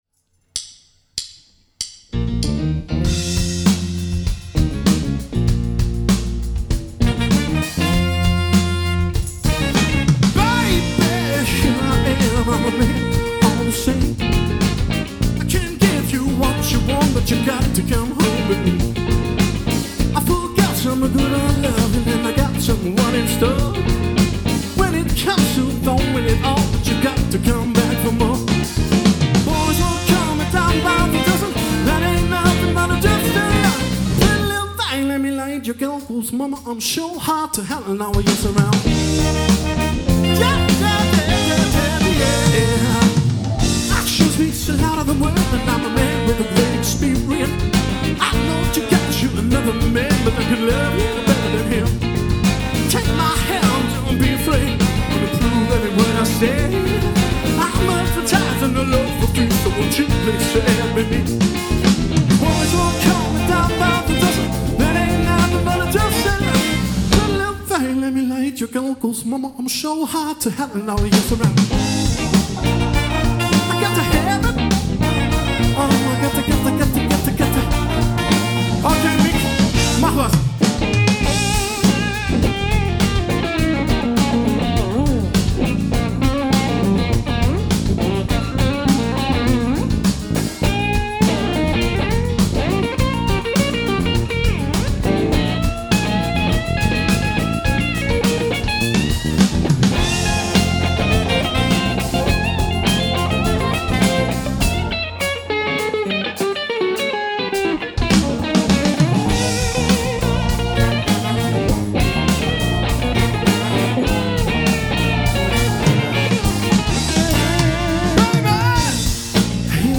as recorded live in Braunschweig
neu interpretiert.